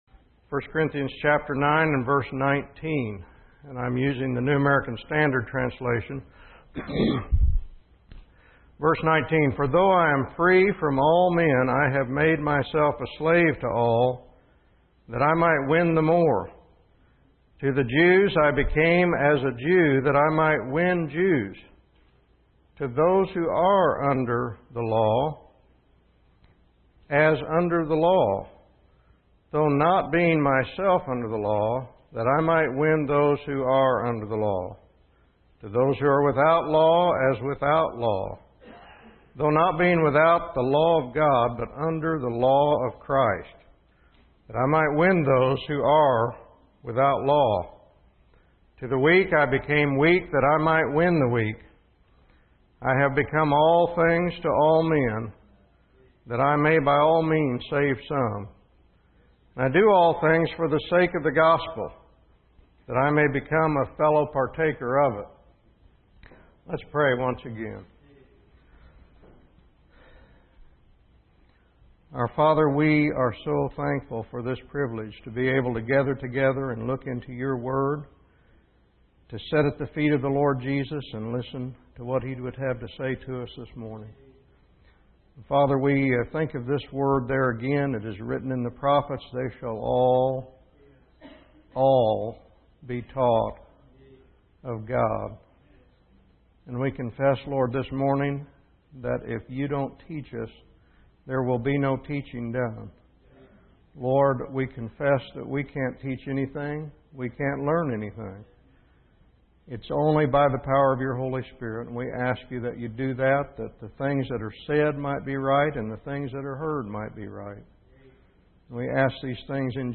In this sermon, the speaker discusses three key principles. Firstly, he addresses the misconception surrounding the term 'law of Moses,' emphasizing that it refers to the law given by God through Moses.